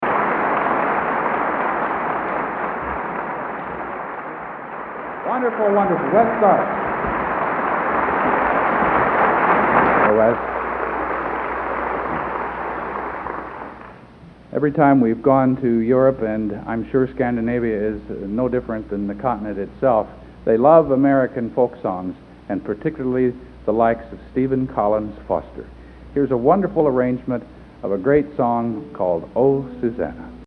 Location: West Lafayette, Indiana
Genre: | Type: Director intros, emceeing |End of Season